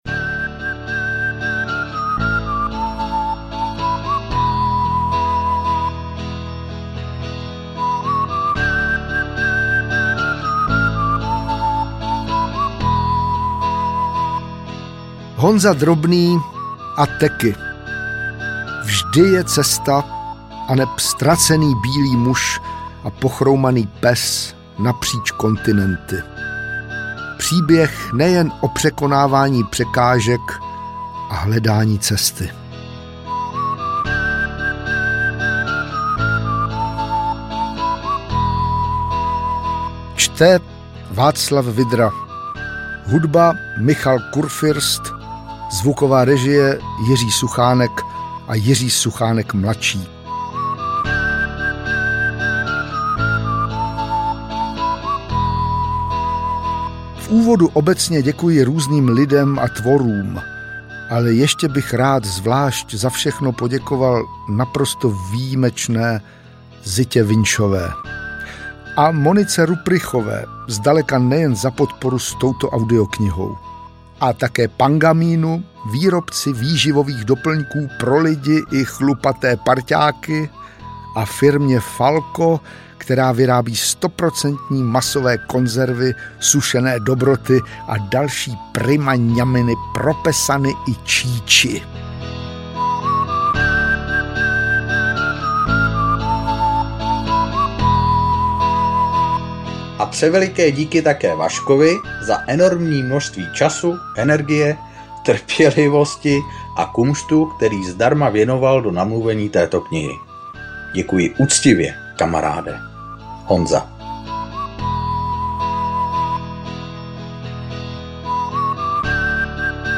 stáhnout ukázku Varianty: Vyberte Audiokniha 399 Kč Kniha vazba: brožovaná 428 Kč Další informace: Čte: Václav Vydra ml.